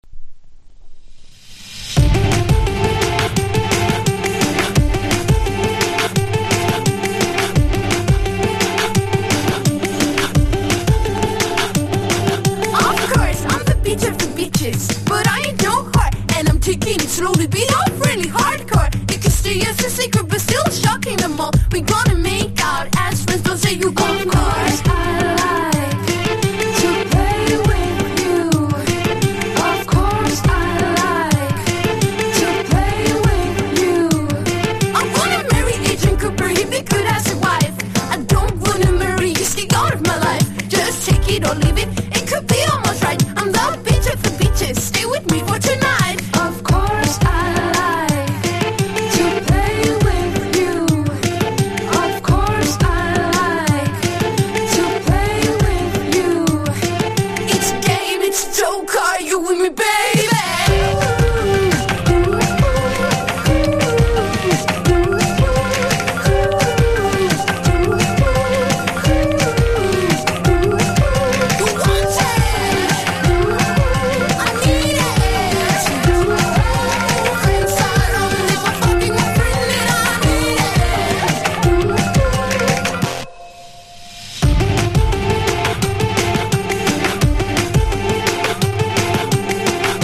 INDIE DANCE# POST PUNK